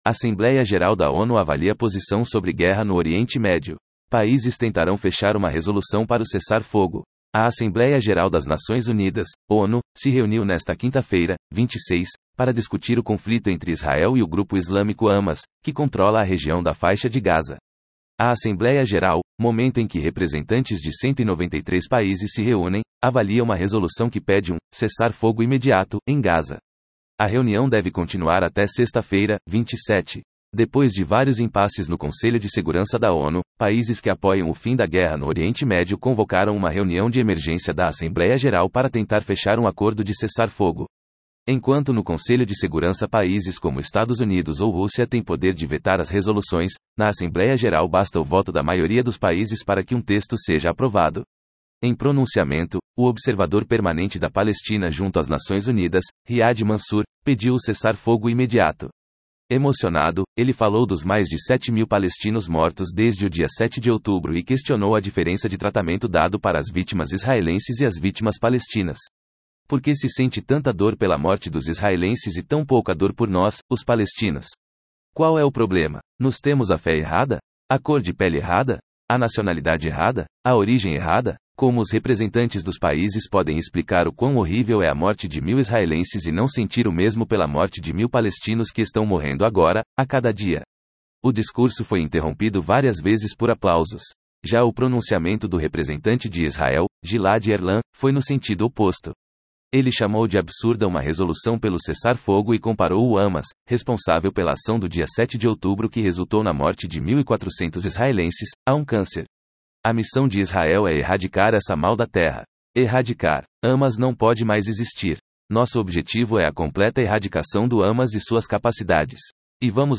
O discurso foi interrompido várias vezes por aplausos.
O discurso foi acompanhado por uma audiência que permaneceu em silêncio todo o tempo.